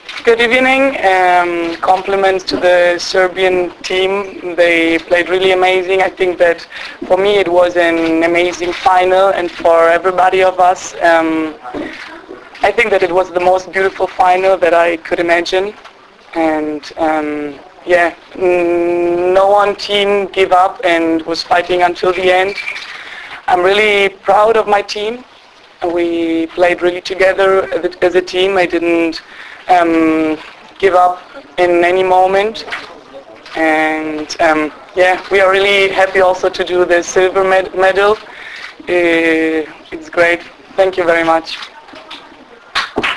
IZJAVA MARGARET ANE KOZUH